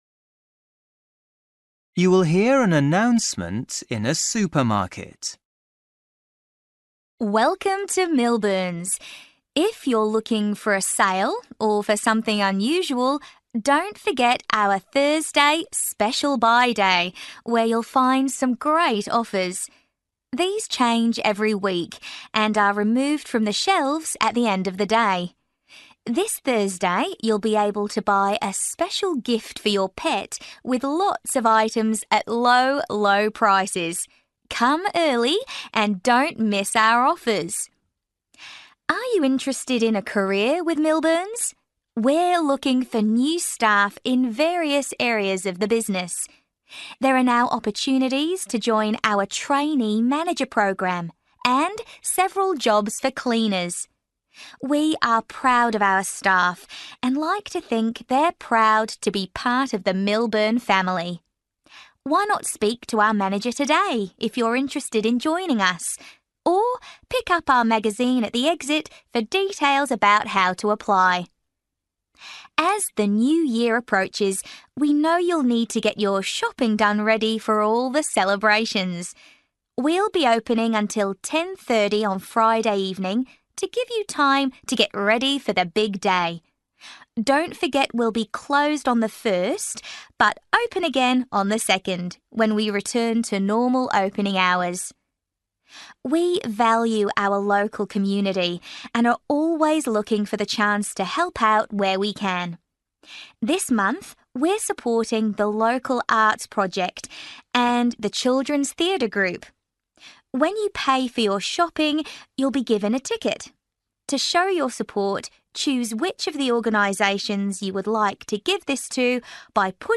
You will hear an announcement in a supermarket.